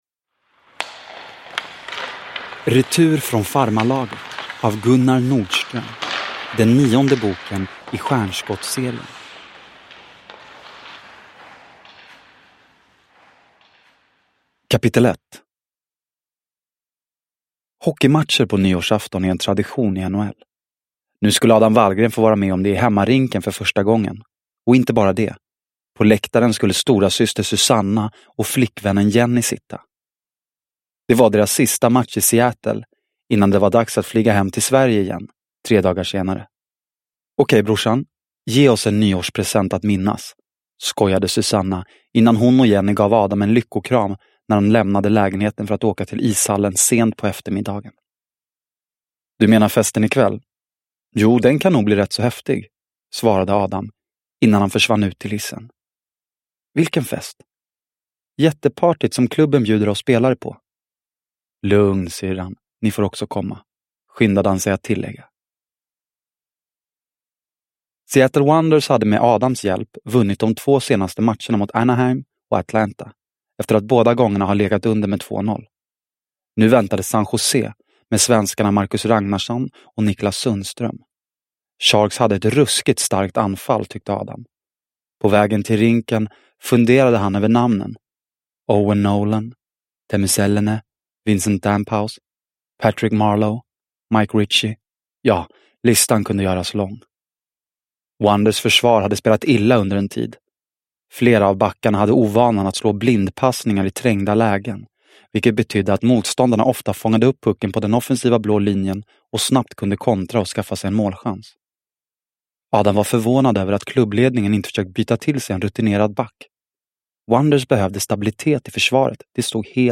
Retur från farmarlaget – Ljudbok – Laddas ner